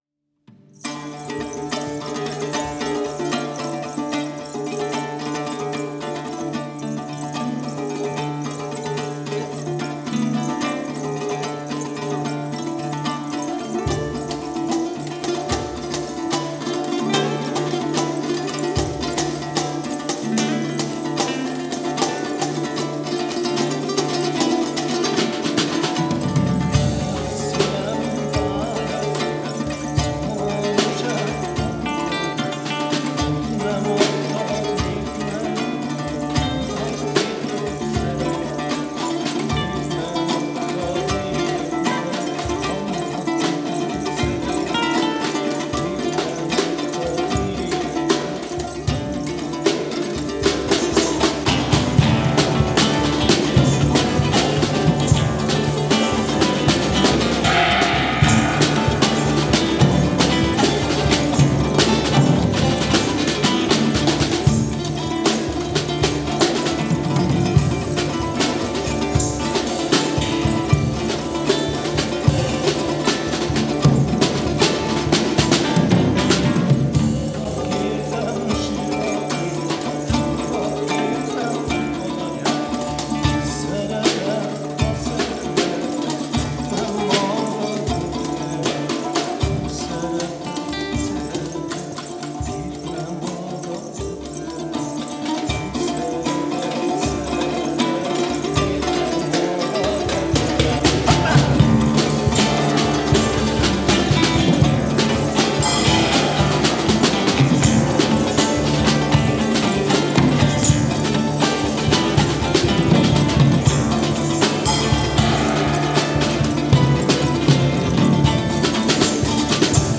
ütőhangszerek
klasszikus és jazz gitár
keleti húros hangszerek (török lant, sitar, dusar)
𝄞 Magyarkanizsai koncertfelvételek